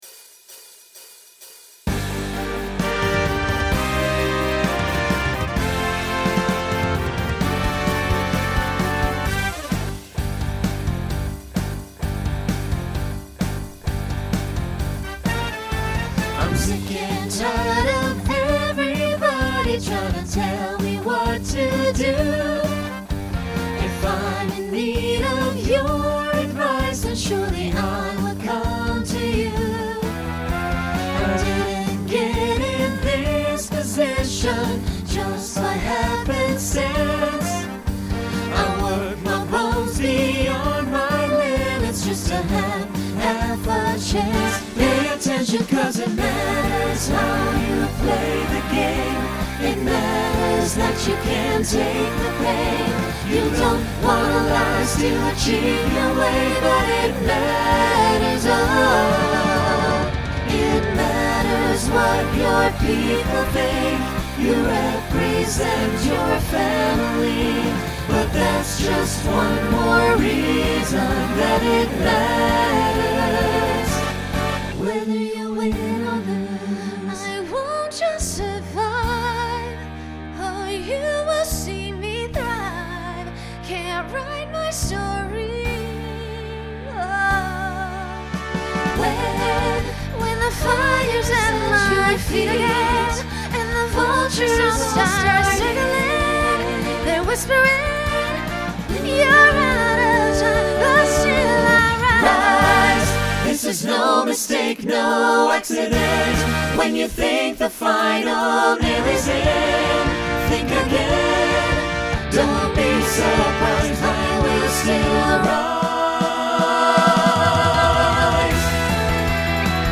Genre Pop/Dance , Rock Instrumental combo
Voicing SATB